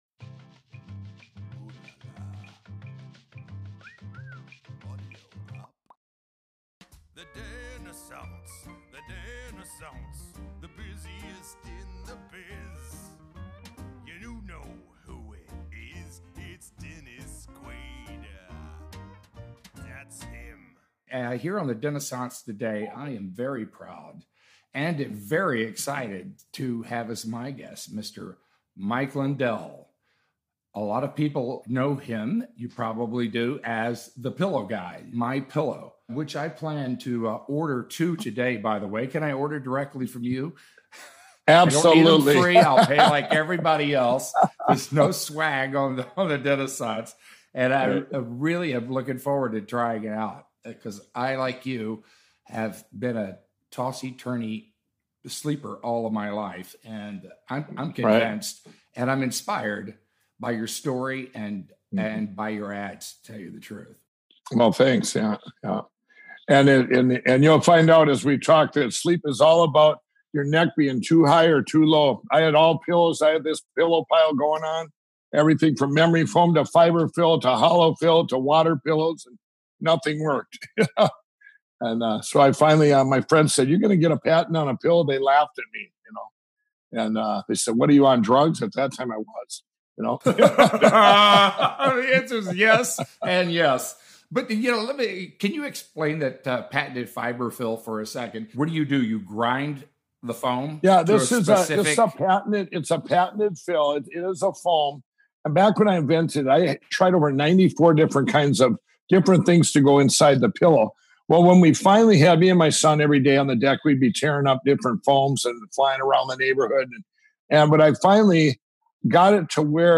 On this episode of The Dennissance, "My Pillow Guy" Mike Lindell joins Dennis for a very candid conversation that covers everything from his crack cocaine addiction and inventing MY PILLOW to how he and Donald Trump became friends.